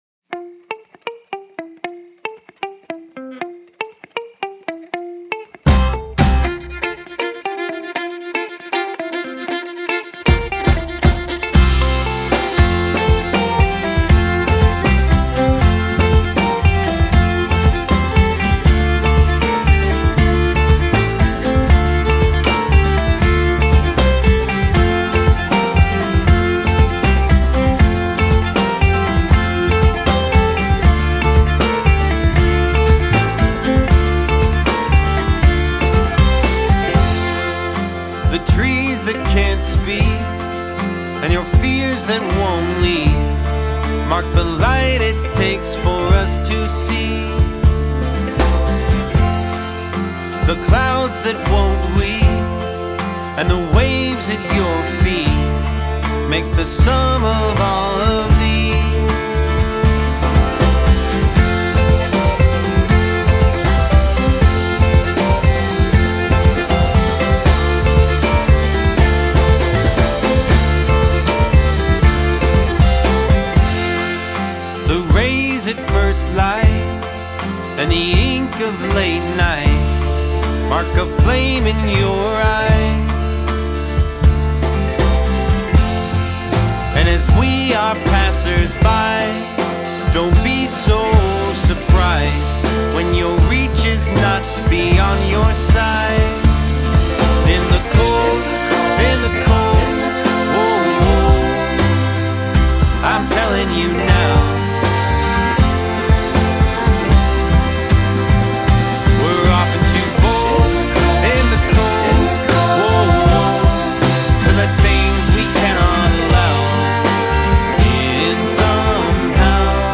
five-piece bluegrass band